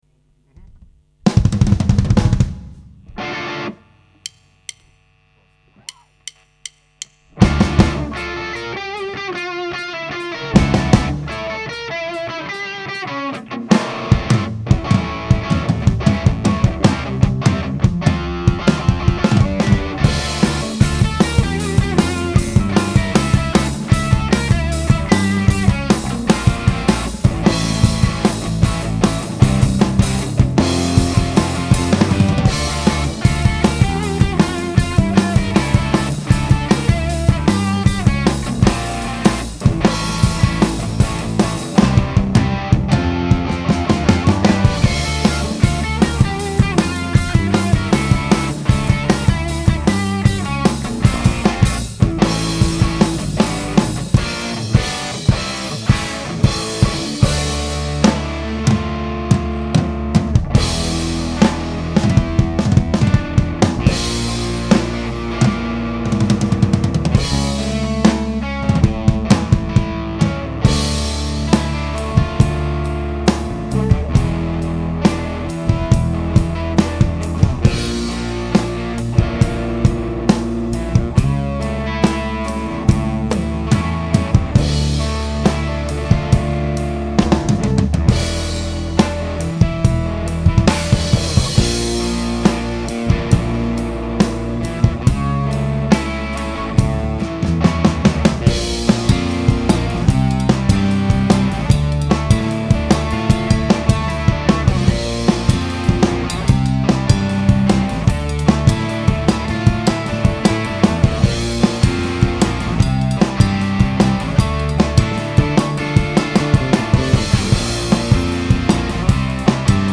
A háromféle végfok keverésével, és a megfelelő bemenő gain eltalálásával ugyanis több, nagyon karakteres, közepesen nagy gain-ű torzítási szövet csalható elő, amik mindegyike kifejezetten harapós, és jól reagál a gitáron való játékra.
És így is van, a 6L6-ok testessége, mélye, párosítva az EL84-ek közepével egy kifejezetten használható hangot eredményez.
Ebben a beállításban benne van a crunch-os kíséret, a tiszta hang, és ha az ember odapörköl egy booster-rel, akkor a szólózás is.
Ettől függetlenül a hangzás hibátlan, az egyes húrokat maximális torzítás mellett is érteni, és a hangerő potira, illetve pengetési dinamikára elképesztően reagál, nagyon ízesen lehet vele játszani.